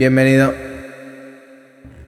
Bienvenido_reverb.ogg